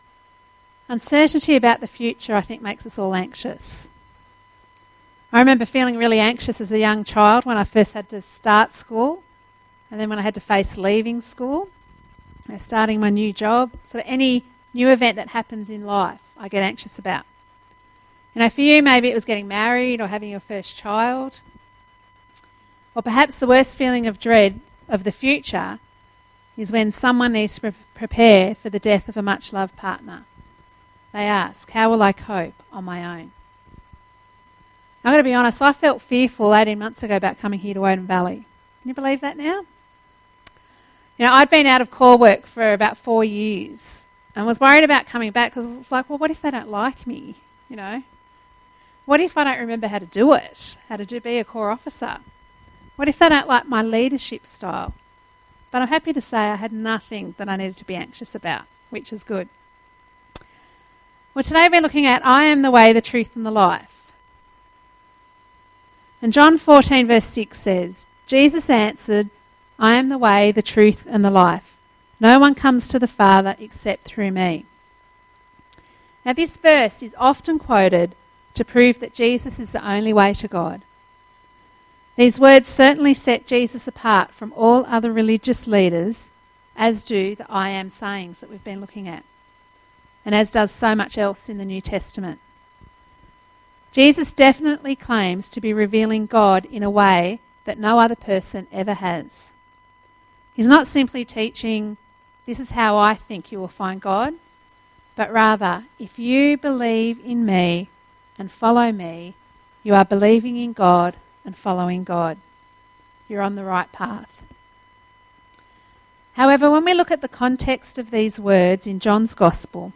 Download audio Subscribe to podcast feed Load podcast into iTunes 05 October 2014 A seven week Sermon Series looks at Jesus' seven "I AM" statements in the Gospel of John.